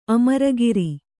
♪ amaragiri